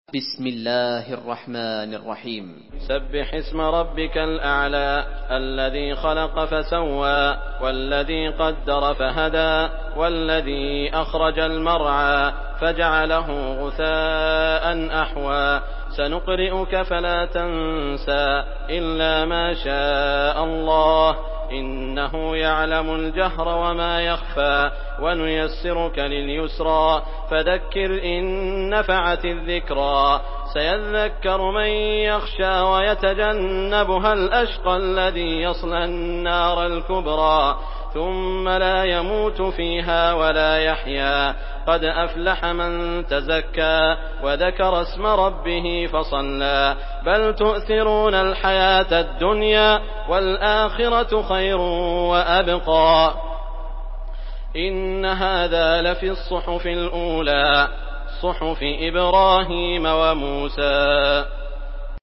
Surah الأعلى MP3 by سعود الشريم in حفص عن عاصم narration.
مرتل